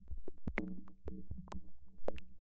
Descarga de Sonidos mp3 Gratis: agua 17.